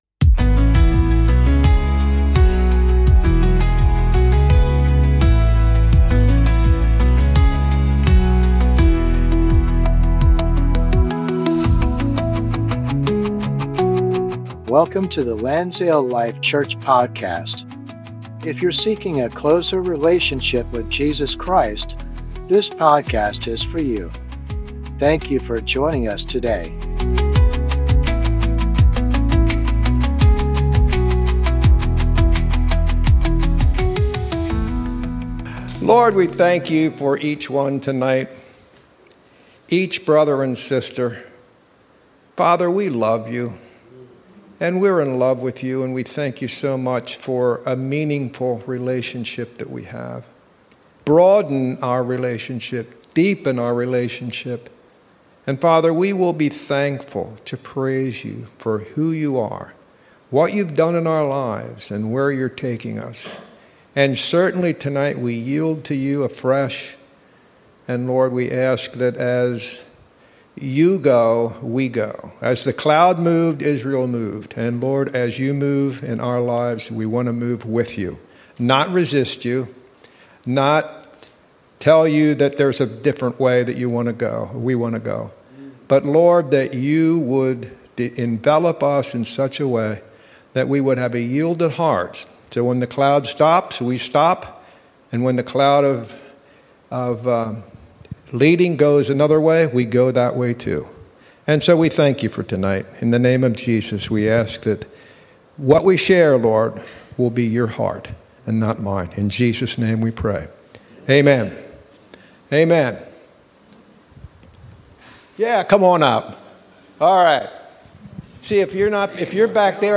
An ongoing bible study on the book of Numbers.